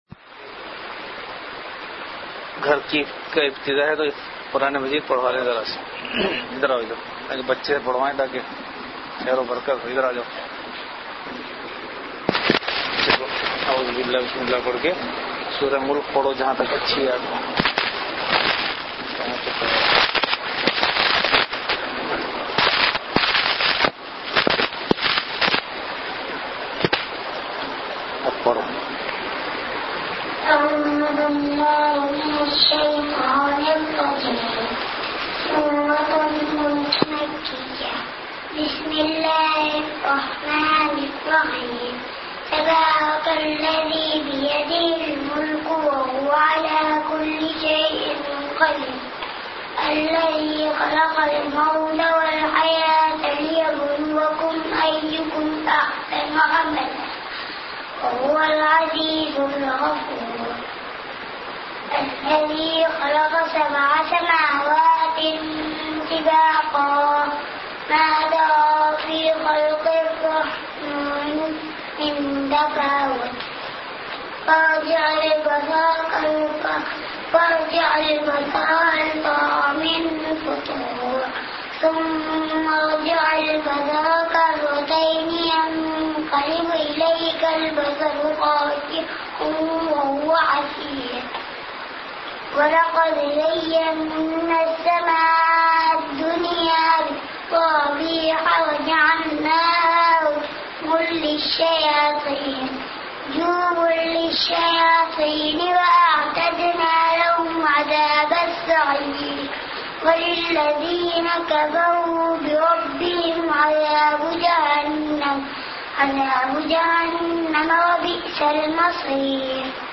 Delivered at Home.
Majlis-e-Zikr · Home Mukhtasir Naseehat
Event / Time After Isha Prayer